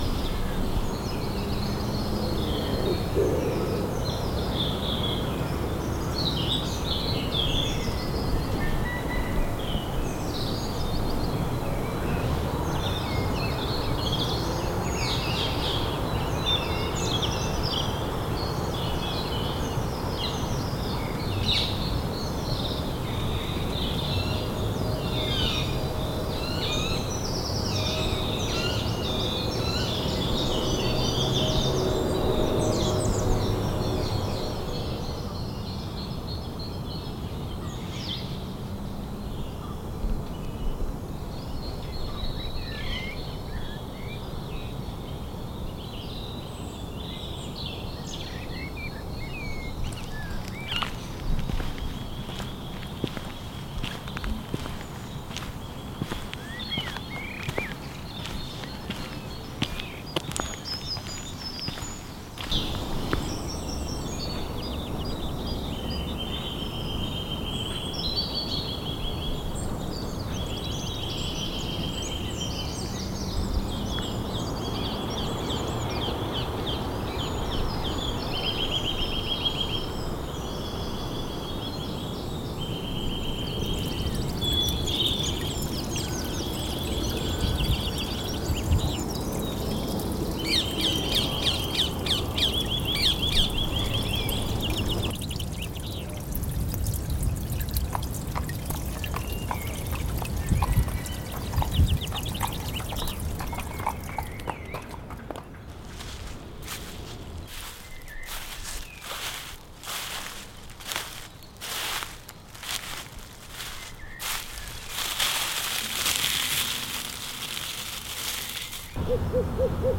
The thing that strikes me most about the first section of the walk is the sounds of the birds in the trees.
A cacophony of tweeting and singing from all sorts of birds is a real treat. And then we cross a small stream, the sounds of the birds are accompanied perfectly by the gentle babbling of the stream.
Listen to the sounds of the sensory walk.
GOLDERS-HILL-SOUND-OF-THE-WALK-AUDIO.mp3